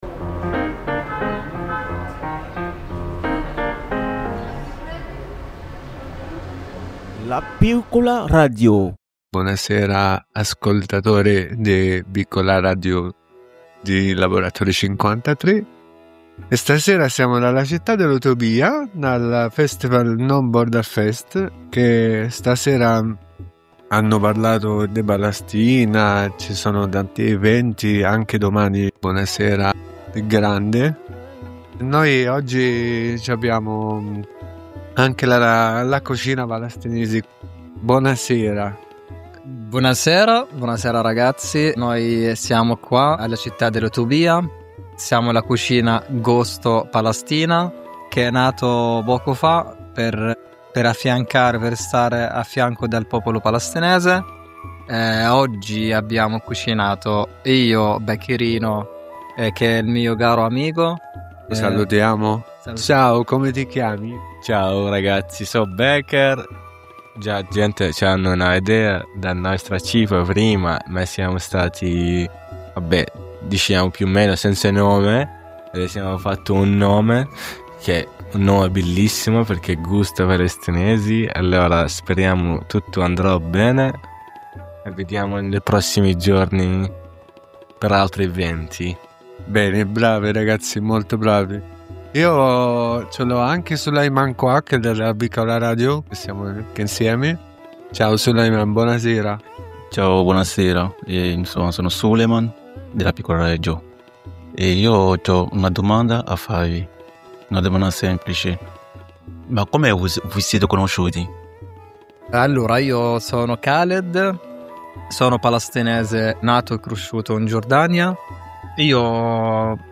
intervista-tra-amici-al-no-border-fest-2025